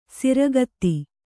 ♪ siragatti